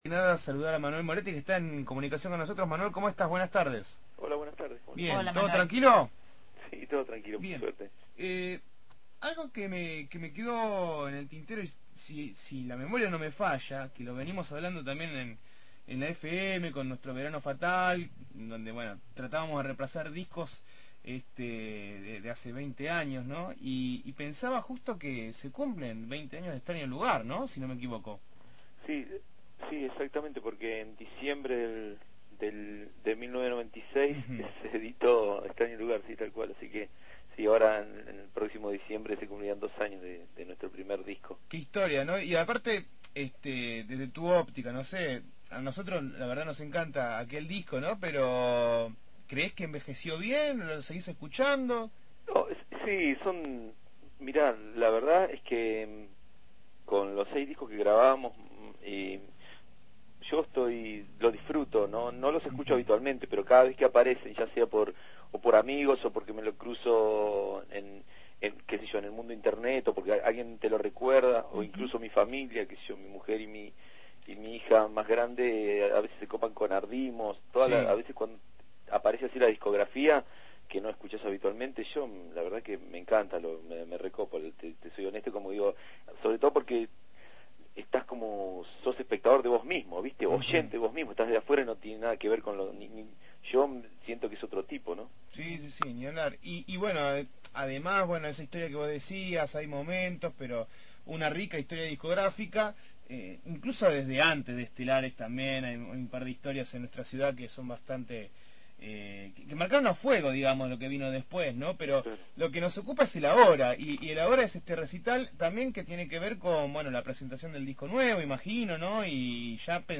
Entrevista al músico Manuel Moretti sobre la presentación de su nuevo disco el 2 de abril.